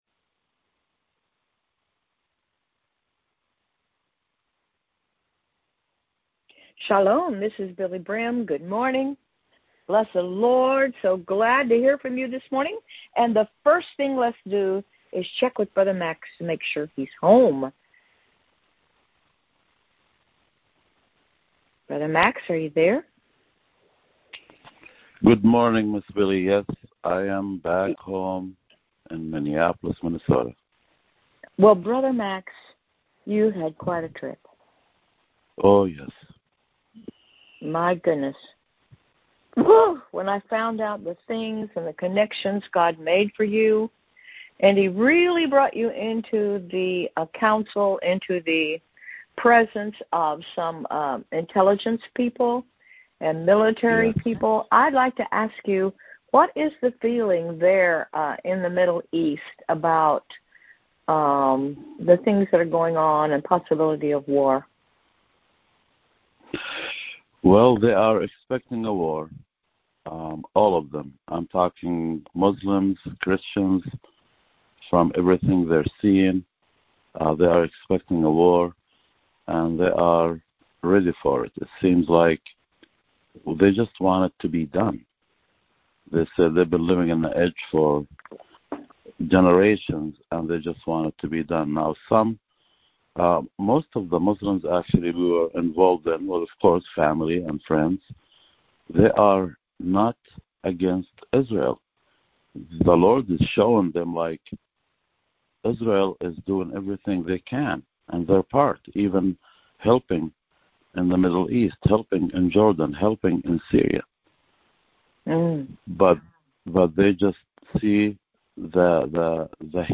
Prayer Call